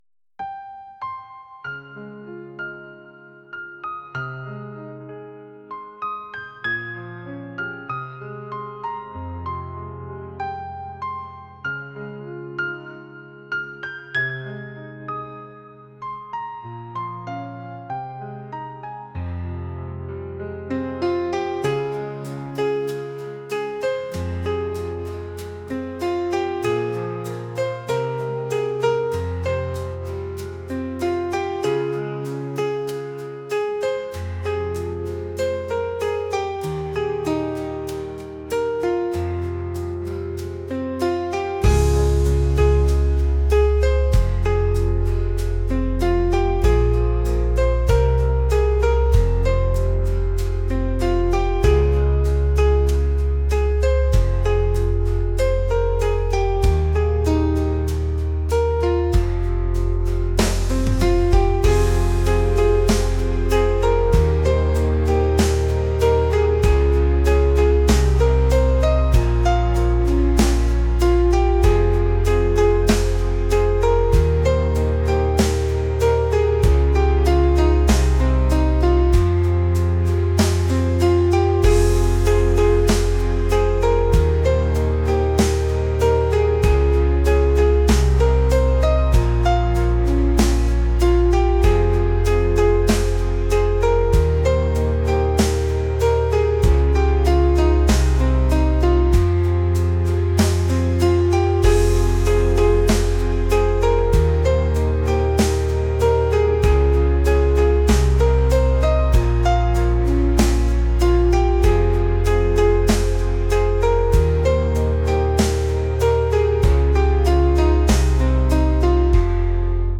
pop | acoustic | soulful